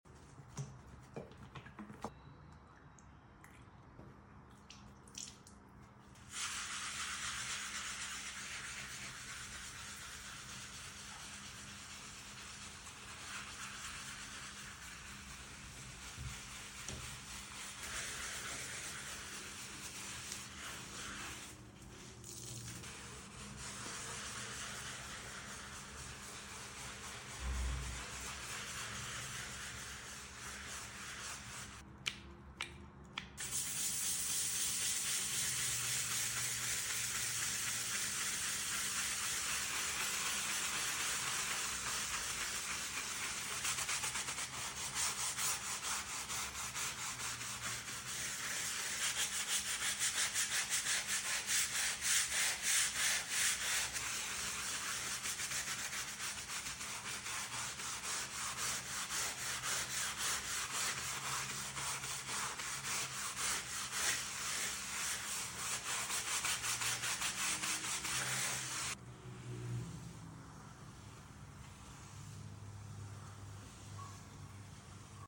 Satisfying ASMR Floor Tile Cleaning sound effects free download
The foam, scrubbing, and sparkling result make this so relaxing to watch. Enjoy the soothing cleaning sounds!